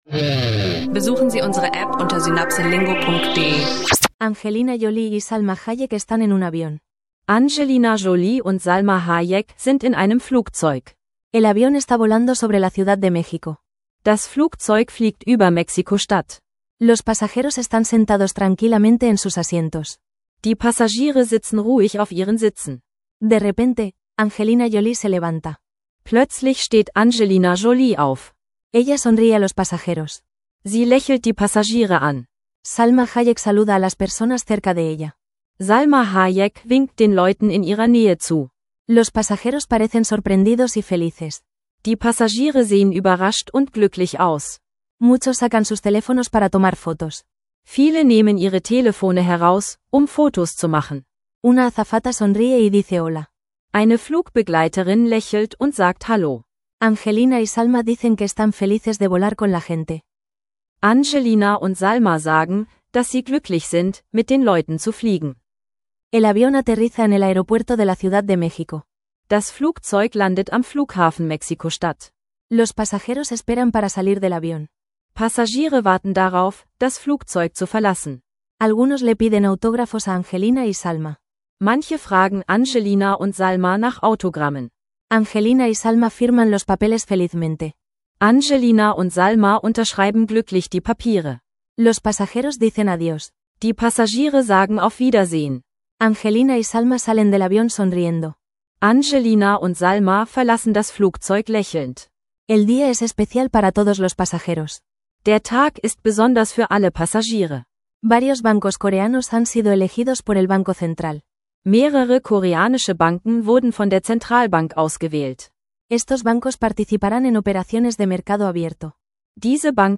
Beschreibung vor 8 Monaten In dieser Folge lernen Sie Spanisch im Alltag mit spannenden Geschichten: Angelina Jolie und Salma Hayek überraschen Passagiere in Mexiko-Stadt, koreanische Banken im Offenmarkt und die Partnerschaft von Nvidia mit AEye. Durch authentische Dialoge und Vokabeln verbessern Sie Ihr Spanisch für den Alltag, unterwegs und im Beruf.